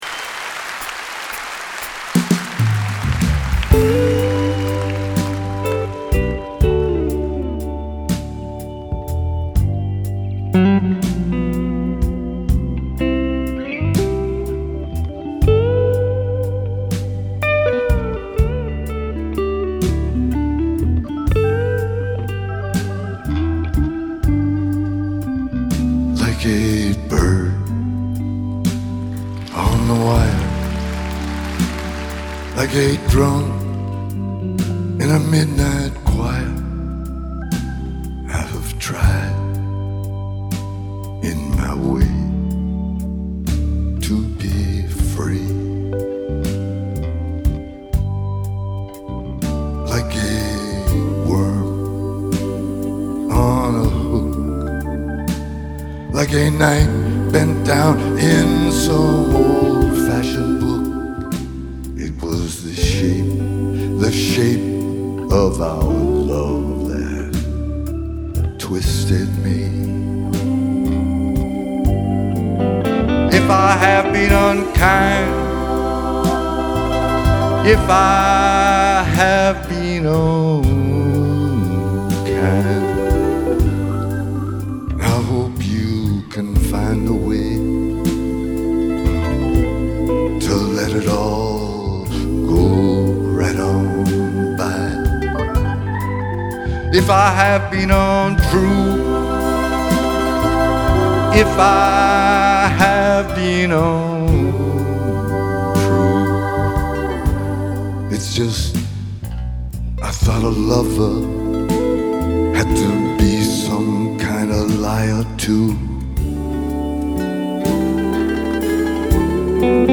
Live Nov 6